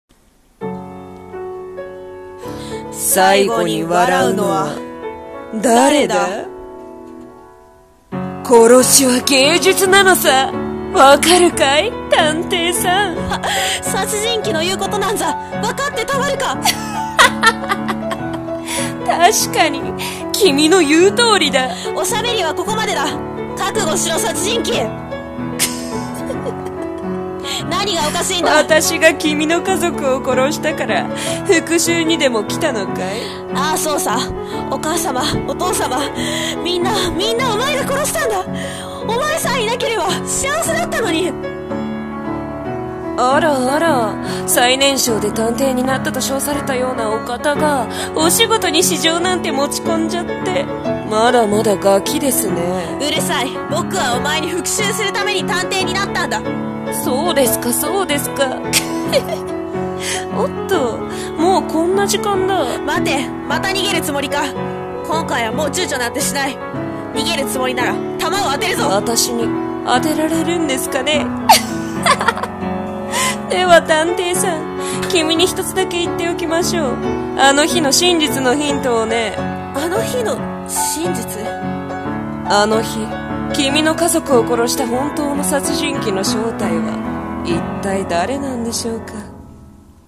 『幕開け』【声劇台本】